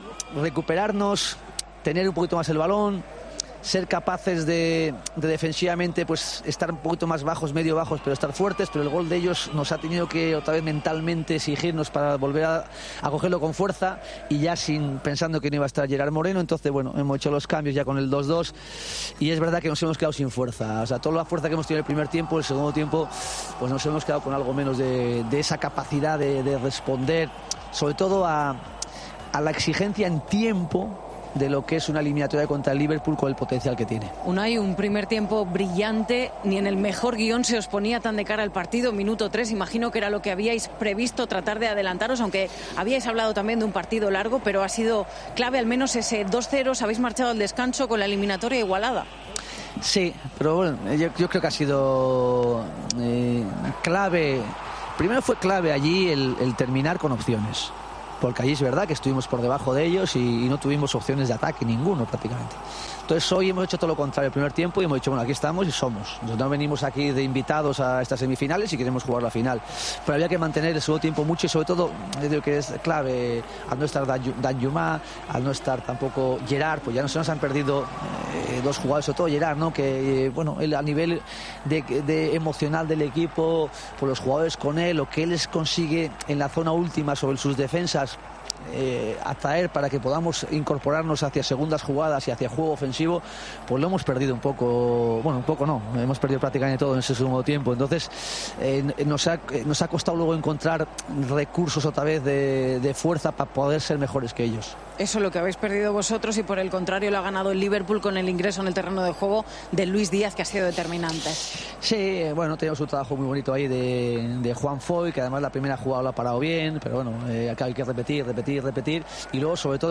AUDIO: El entrenador del Villarreal no ha querido hablar de la actuación arbitral en el micrófono de Movistar tras caer ante el Liverpool.